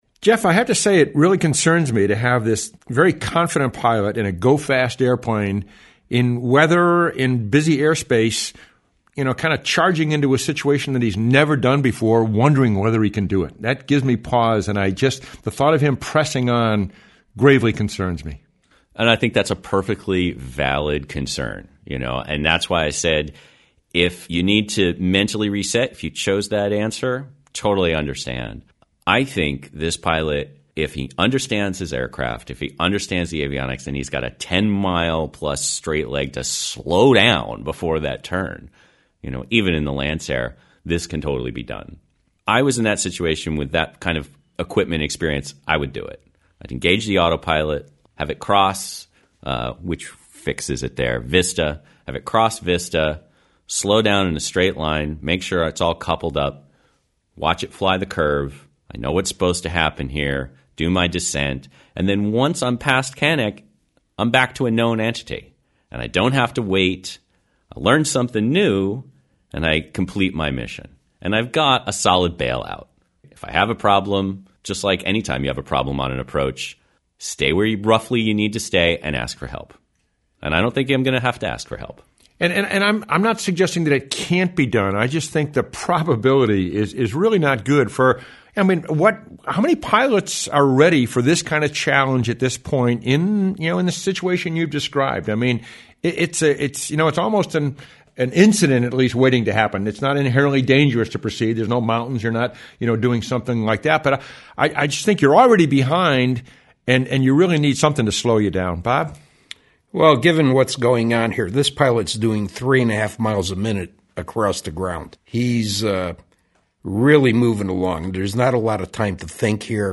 79_Curve Ball at Palomar_roundtable.mp3